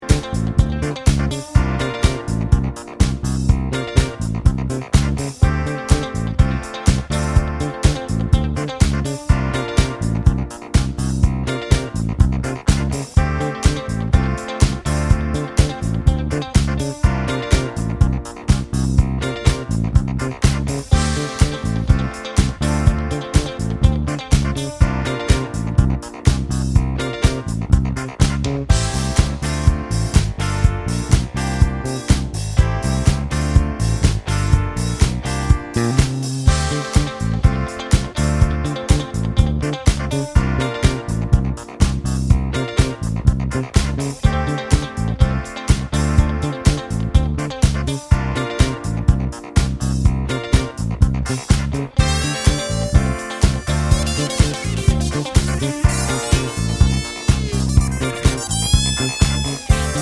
no Backing Vocals Disco 4:02 Buy £1.50